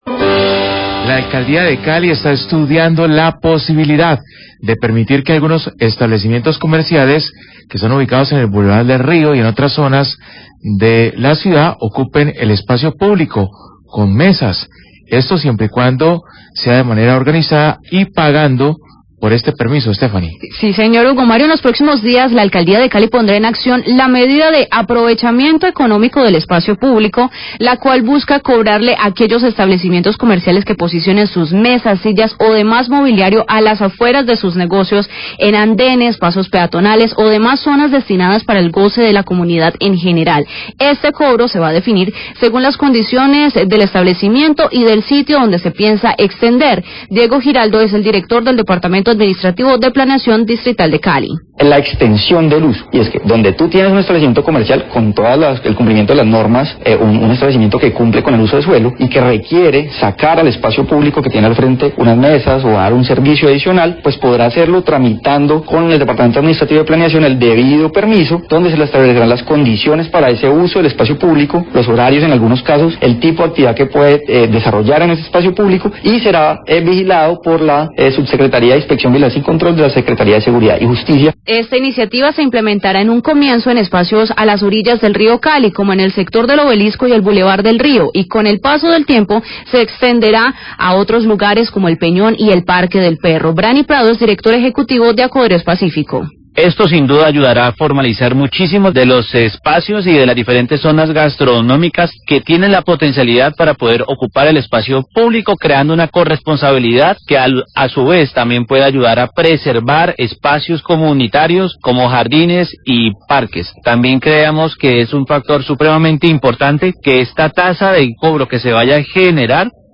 Radio
La Alcaldía de Cali estudia la posibilildad de que algunos locales comerciales del Bulevar del Río y de otras zonas de la ciudad, hagan uso del espacio publico con mesas, siempre y cuando sea de manera organizada y pagando por este beneficio.  El director de la Oficina de Planeación de Cali, Diego Giraldo, explica cómo funcionará esta iniciativa.